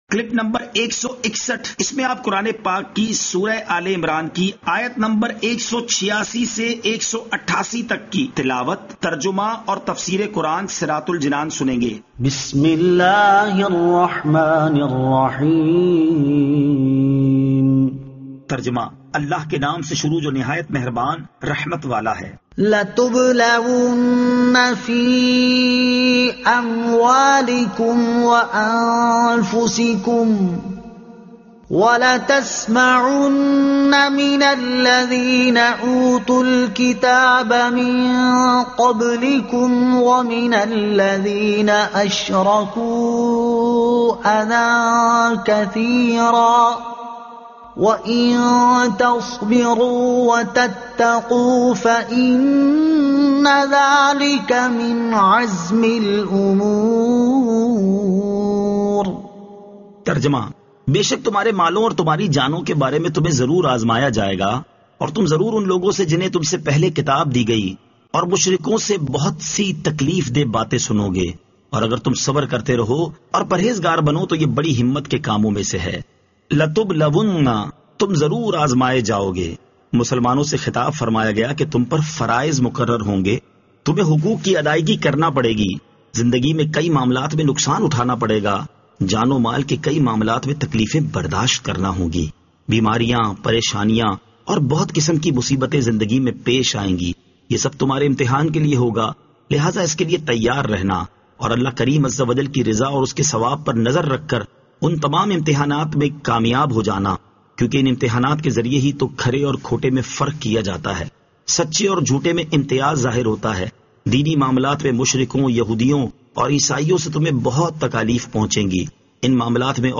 Surah Aal-e-Imran Ayat 186 To 188 Tilawat , Tarjuma , Tafseer